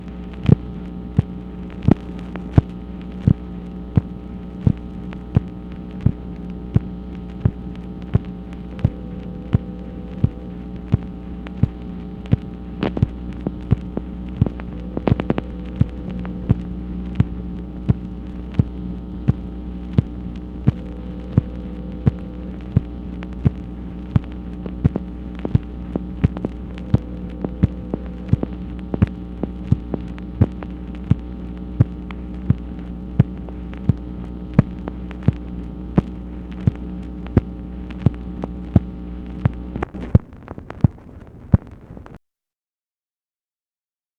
MACHINE NOISE, October 16, 1966
Secret White House Tapes | Lyndon B. Johnson Presidency